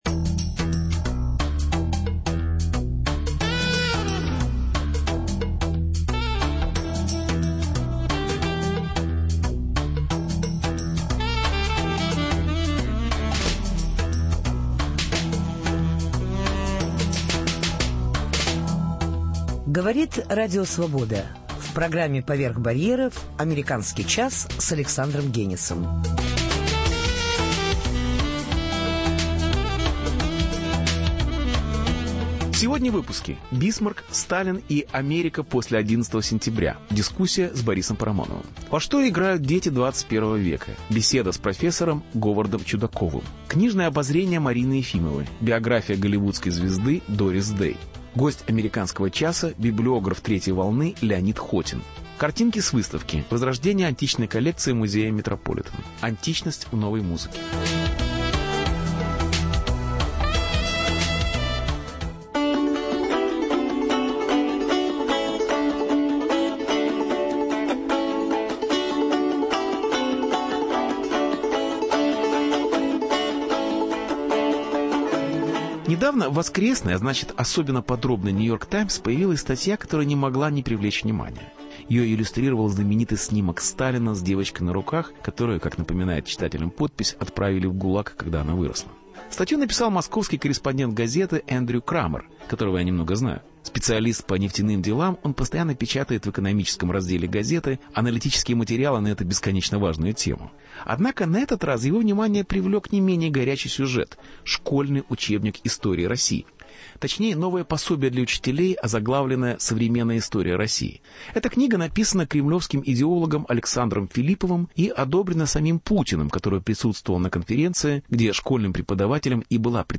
Беседу ведет Александр Генис.